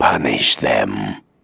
That's the voice of the Helix Chopper from China in Generals : ZH, Though I guess that unit wasn't as memorable as the Overlord or the SCUD Launcher maybe?